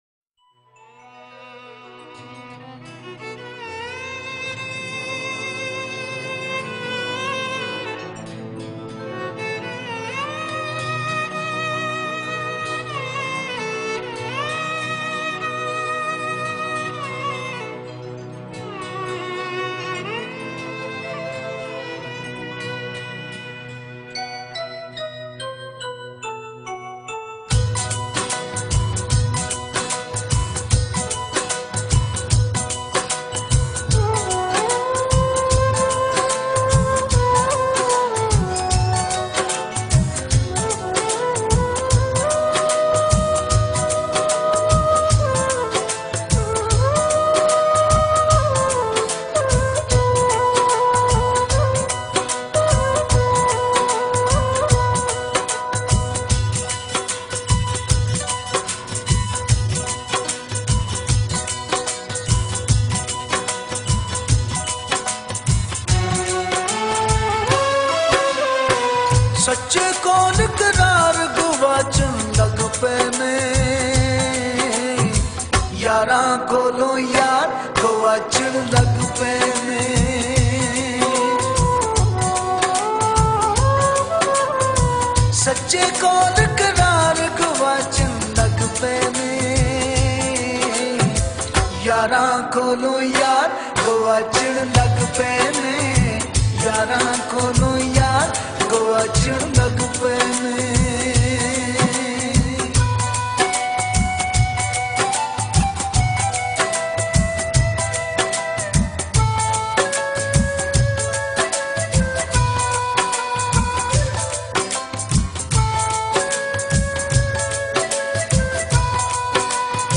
Punjabi Folk and Sufi singing